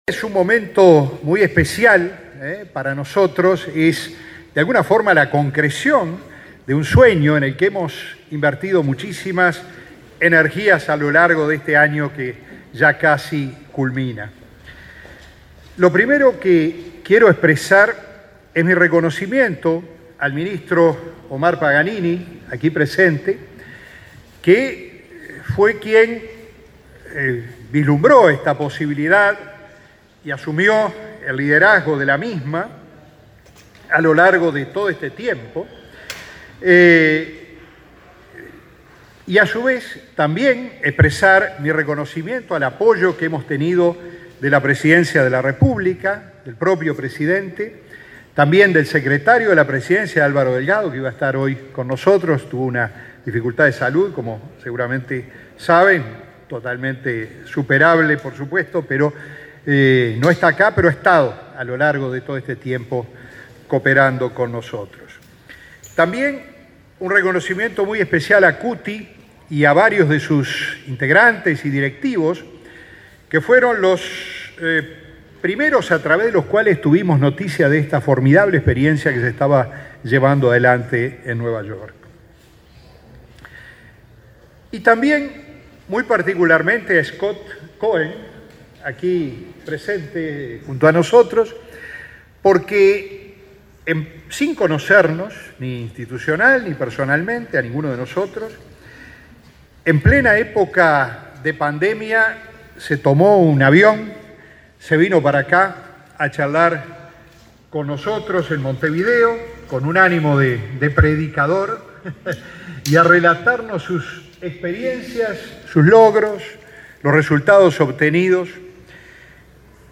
Presentación de NewLab Studios Uruguay 30/11/2021 Compartir Facebook X Copiar enlace WhatsApp LinkedIn Este martes 30, se realizó la presentación de NewLab Studios Uruguay, que funcionará en el parque tecnológico del LATU. Se trata de una alianza público-privada para fomentar la innovación, explicaron el presidente del laboratorio, Ruperto Long; el presidente de la Agencia Nacional de Investigación e Innovación (ANII), Flavio Caiafay y el ministro de Industria, Omar Paganini.